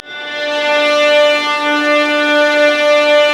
Index of /90_sSampleCDs/Roland L-CD702/VOL-1/STR_Vlas Bow FX/STR_Vas Sul Pont